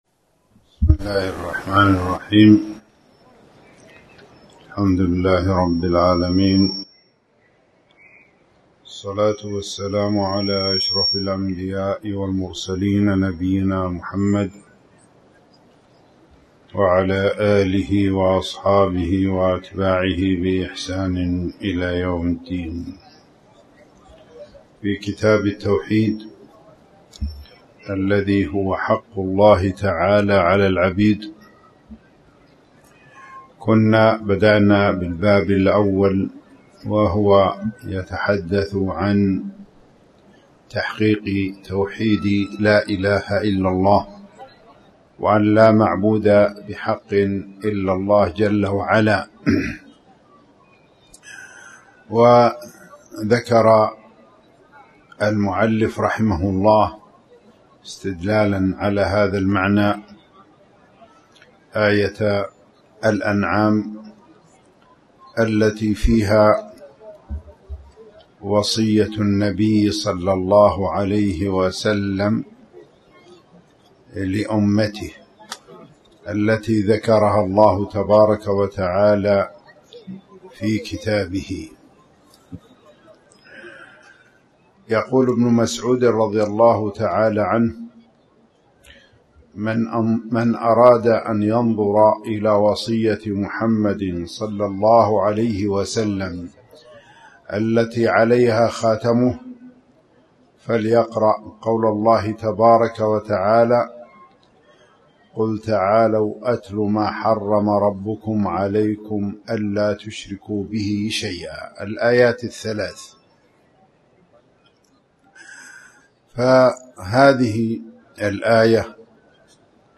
تاريخ النشر ١٨ محرم ١٤٣٩ هـ المكان: المسجد الحرام الشيخ